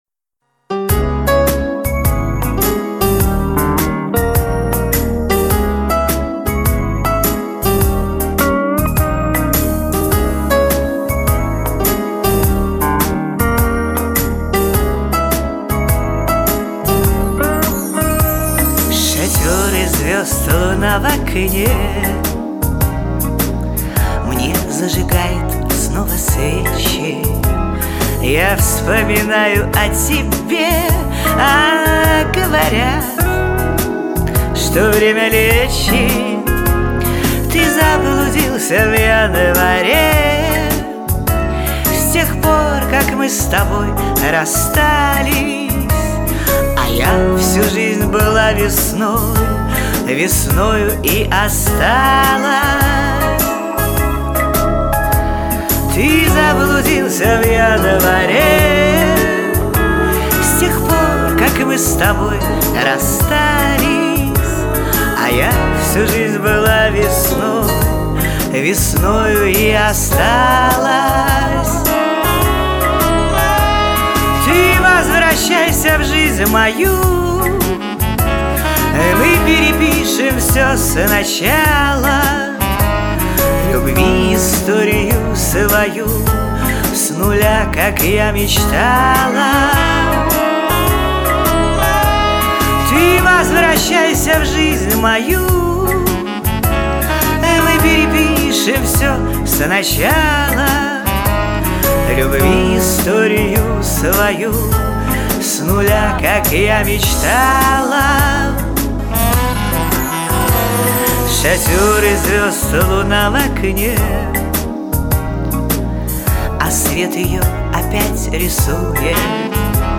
Голос фантастический у Вас
Голос и манера исполнения очень понравились...